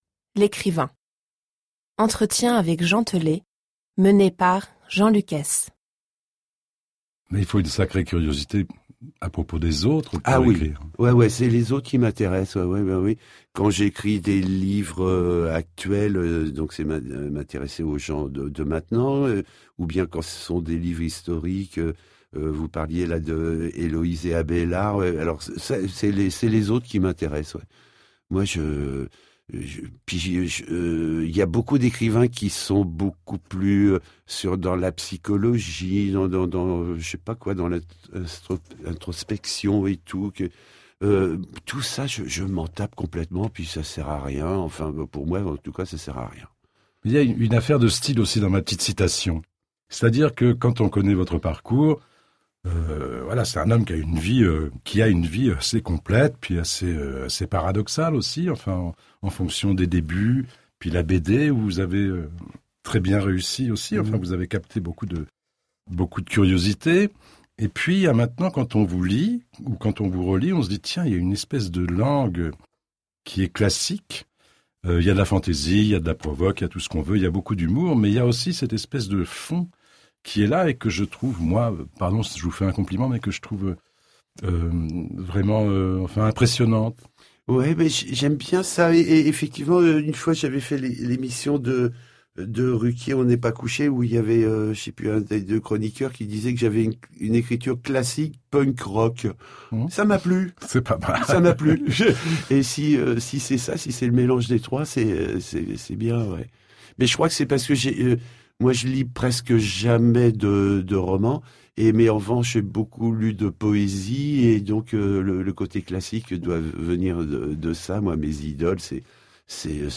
L'Ecrivain - Jean Teulé - Entretien inédit par Jean-Luc Hees